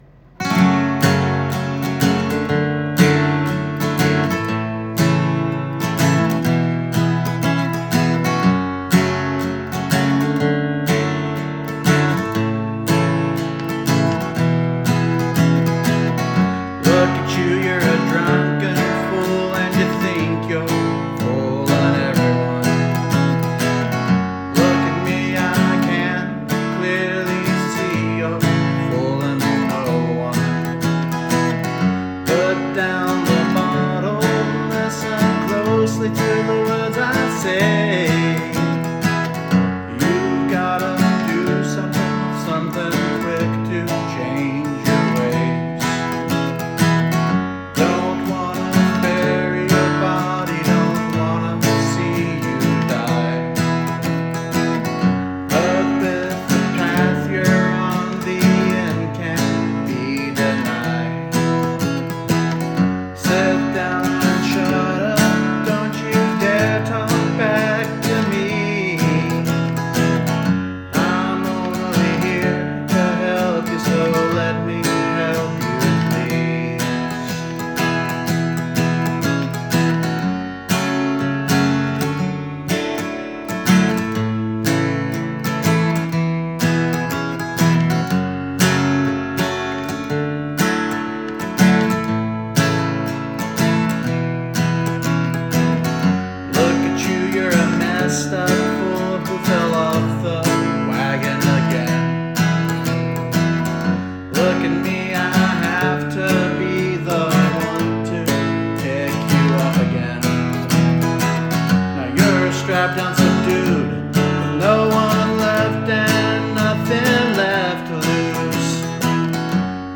singersongwriter acoustic guitar rock country folk